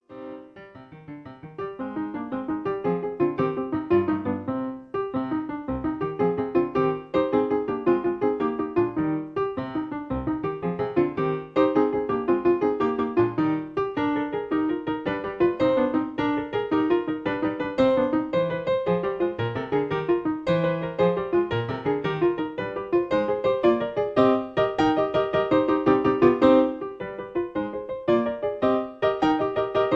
Original key (C). Piano Accompaniment